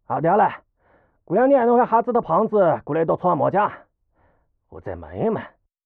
c02_6偷听对话_癞子_3_fx.wav